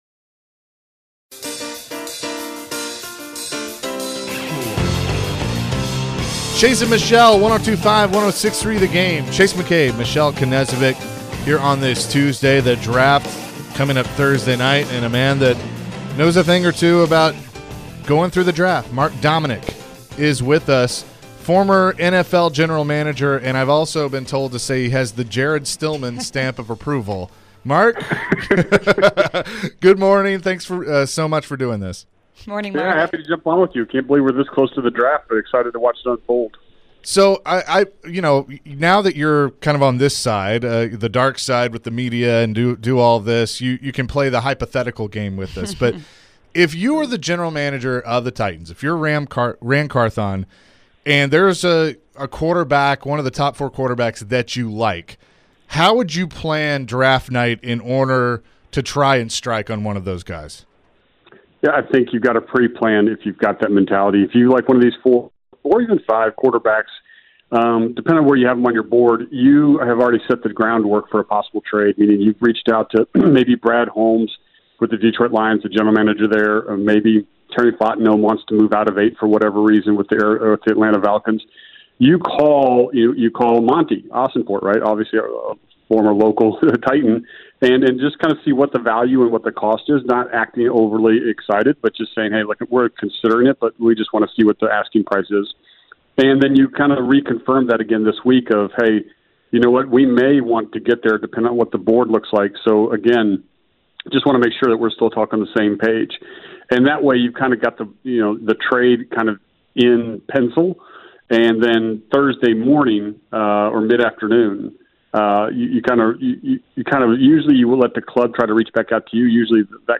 Mark Dominik Interview (4-25-23)